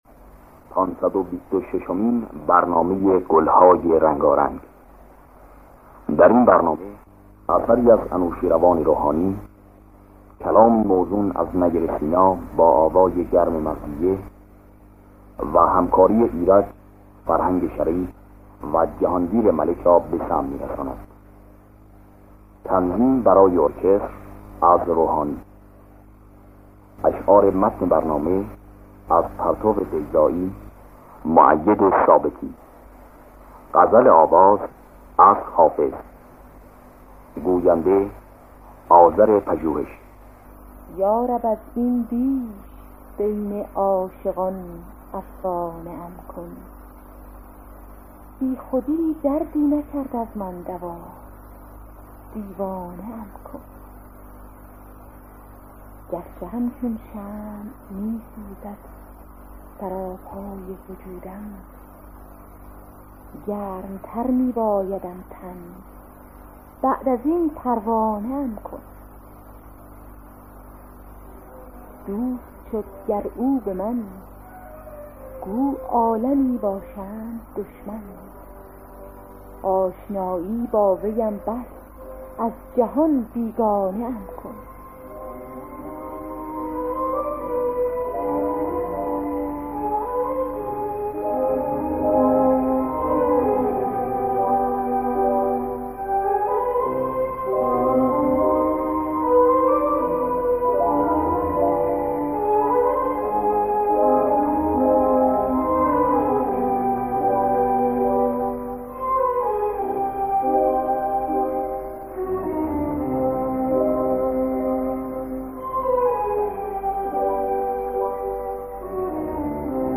در دستگاه بیات اصفهان